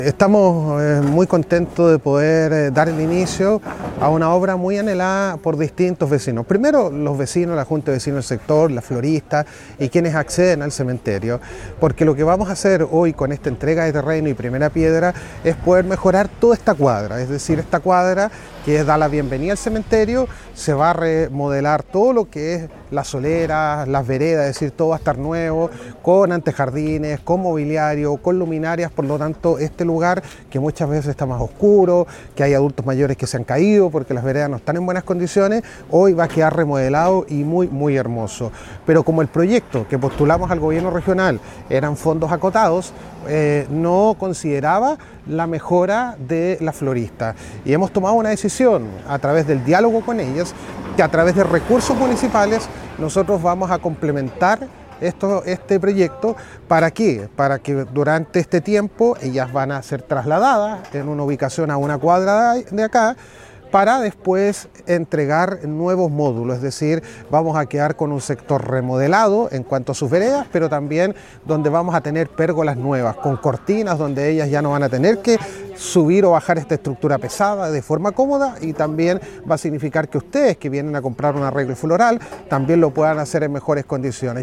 Al respecto, el alcalde de Illapel, Denis Cortés Aguilera destacó la iniciativa.